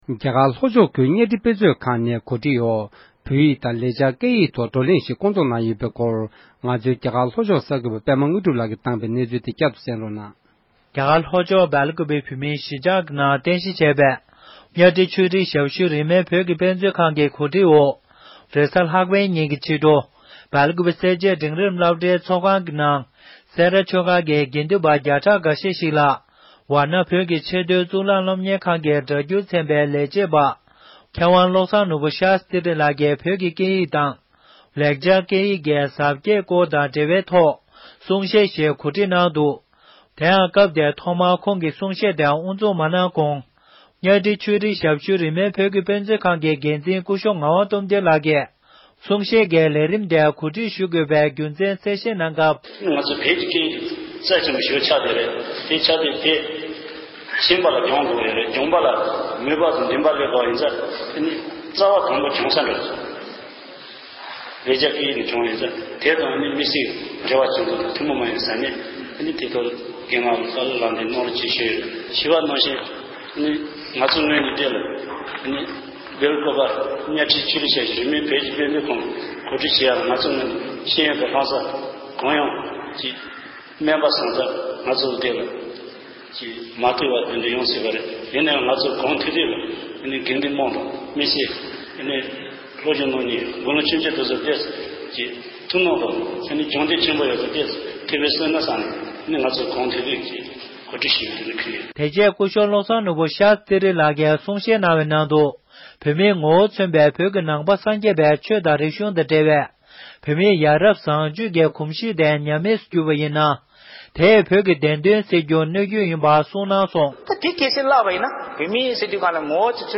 ང་ཚོའི་སྒྲ་འཇུག་ཁང་དུ་གླེང་མོལ་གནང་བཞིན་པ།
སྒྲ་ལྡན་གསར་འགྱུར།